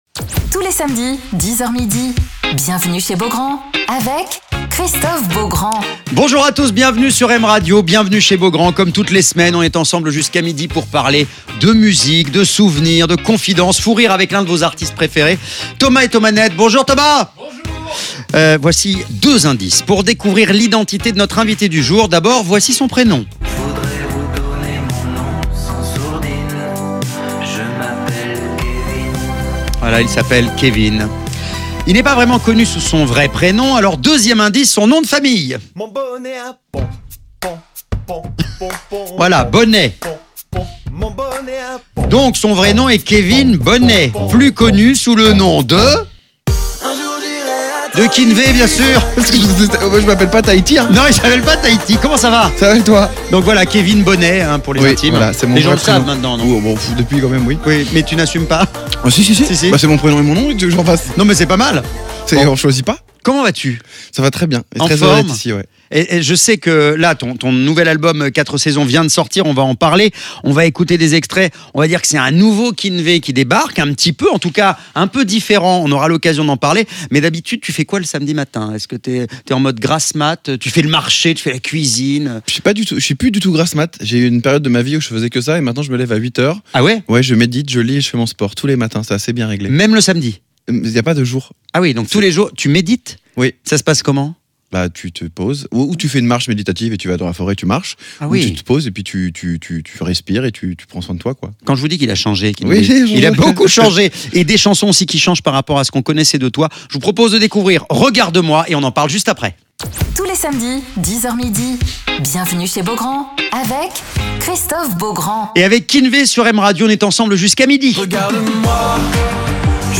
Alors qu'il vient de sortir son album "IV Saisons", Keen'V est l'invité de Christophe Beaugrand sur M Radio